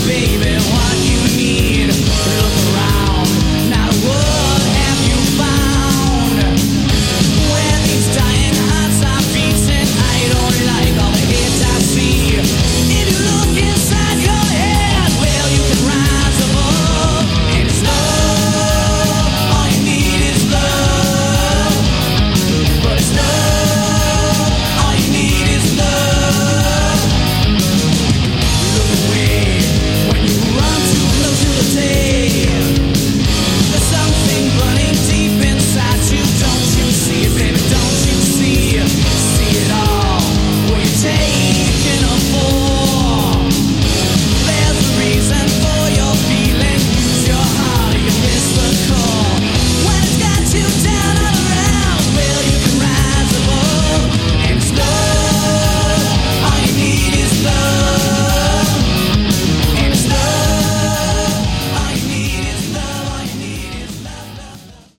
Category: Hard Rock
lead and backing vocals
bass, backing vocals
lead and rhythm guitar, backing vocals
drums